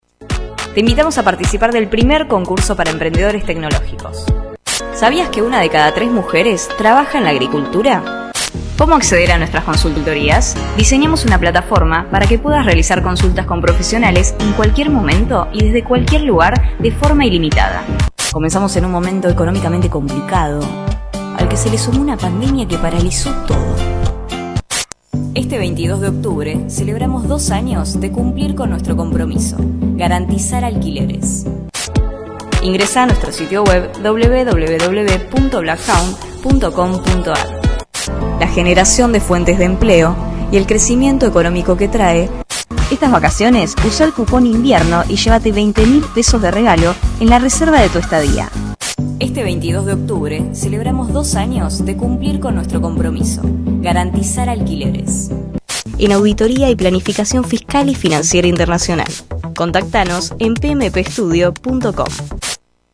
Locutora Nacional
Voz dulce y juvenil para todo tipo de comerciales